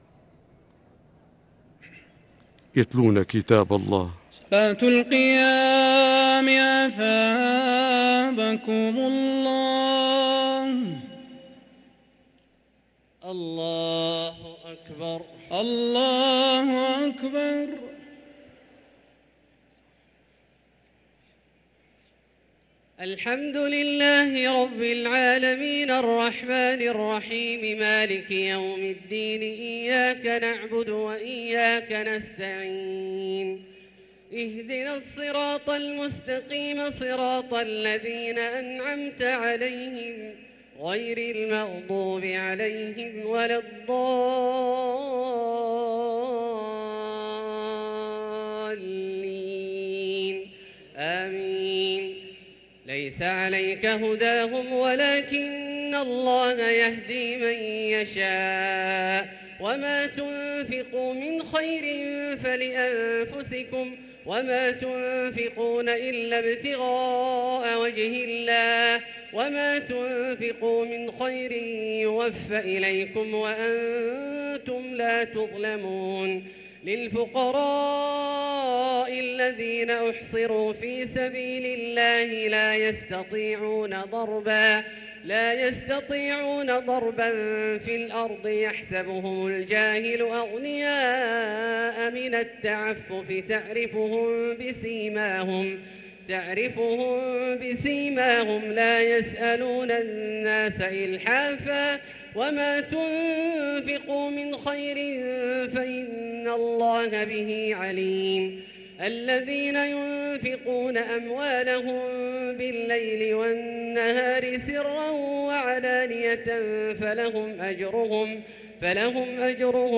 صلاة التراويح ليلة 4 رمضان 1444 للقارئ عبدالله الجهني - الثلاث التسليمات الأولى صلاة التراويح